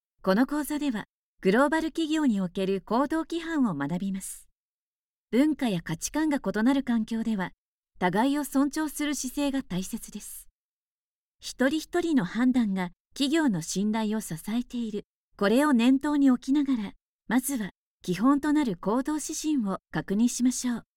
中低音～低音の響く声が特徴です。透明感のあるナレーションや、倍音を活かした語りを得意としています。
元気な、勢いのある
e-ラーニング